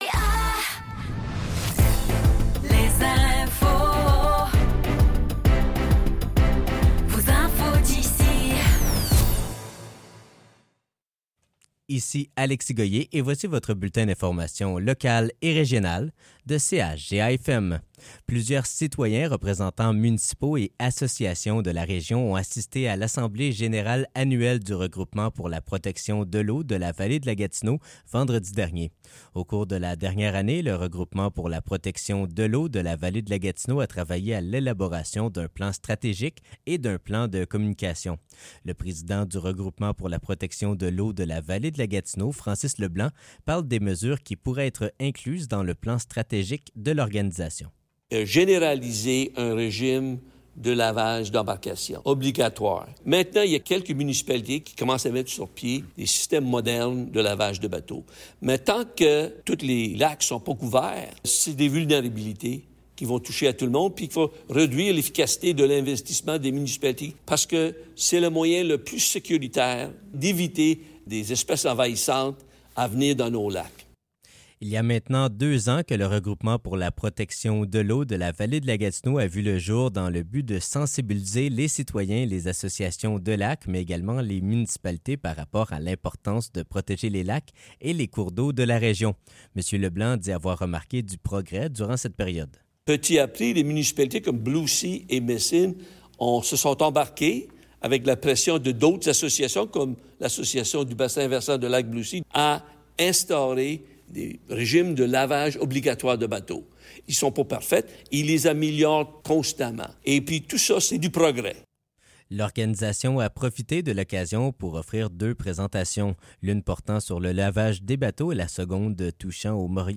Nouvelles locales - 9 septembre 2024 - 15 h